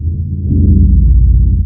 drone6.wav